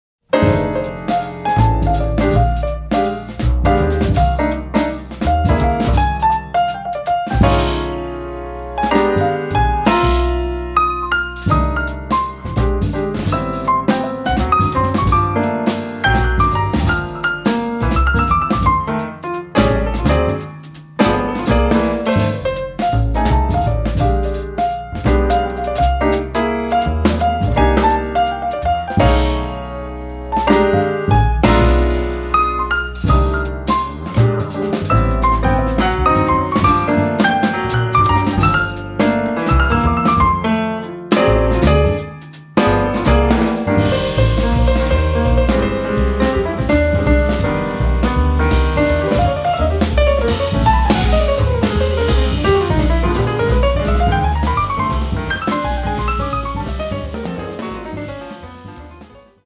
Recorded and mixed in Bergamo, Italy, in December 2008
pianoforte
contrabbasso
batteria